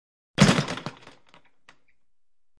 Звуки бокса
Звук удара по подвесной боксерской груше